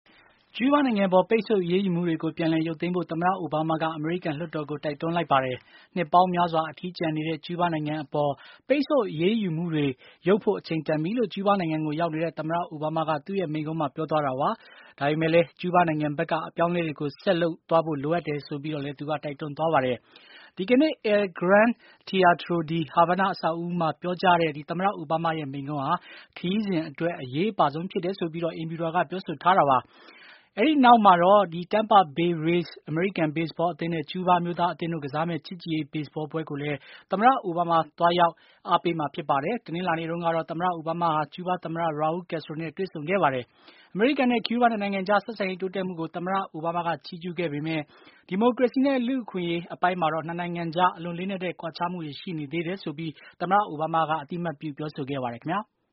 ကျူးဘားရောက် သမ္မတအိုဘားမား မိန့်ခွန်းပြောကြား